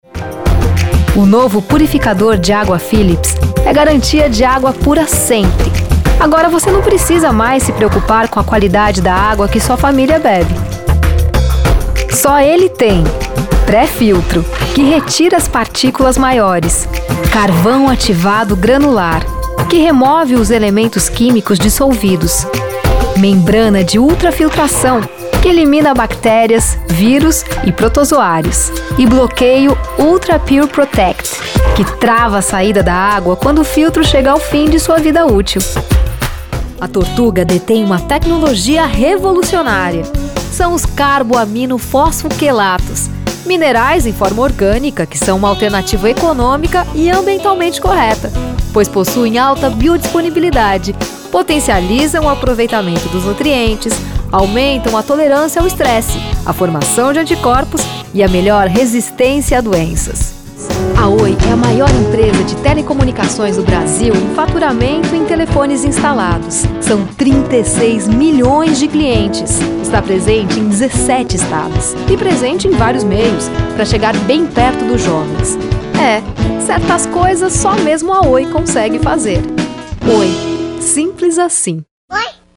Friendly, honest, natural, Brazilian voice
Sprechprobe: Industrie (Muttersprache):